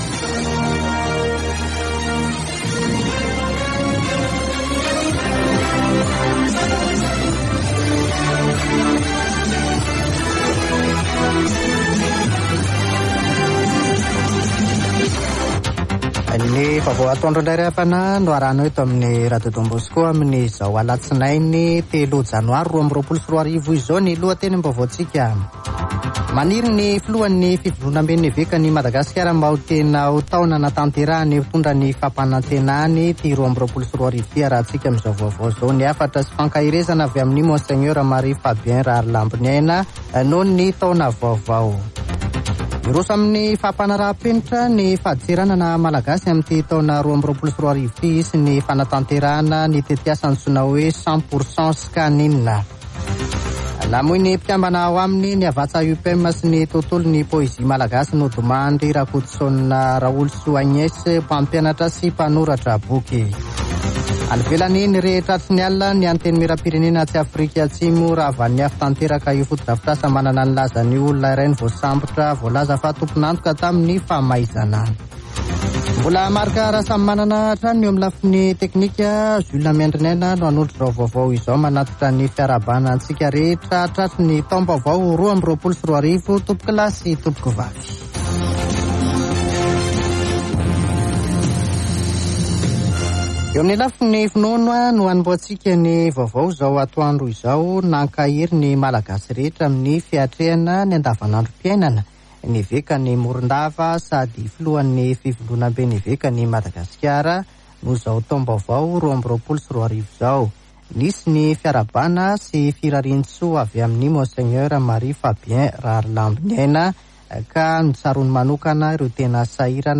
[Vaovao antoandro] Alatsinainy 3 janoary 2022